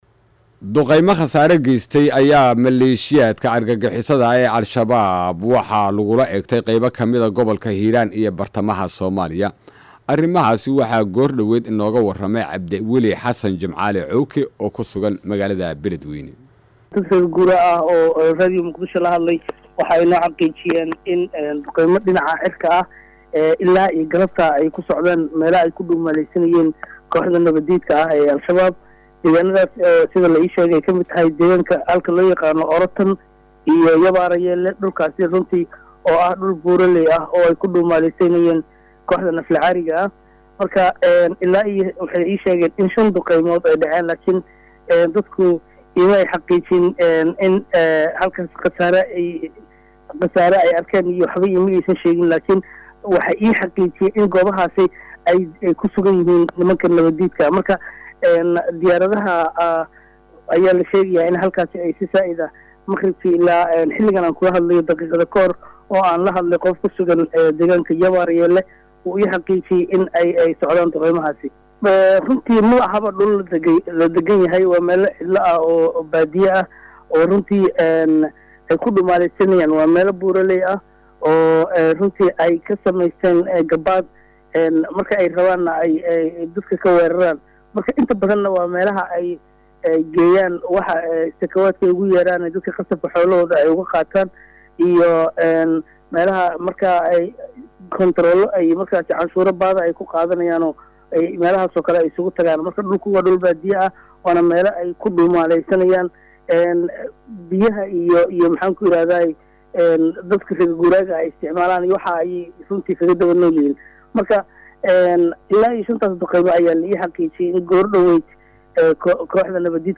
Halkan ka dhageyso Wareysiga Wariye